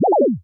rhinodeath_01.wav